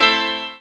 Dub Piano.wav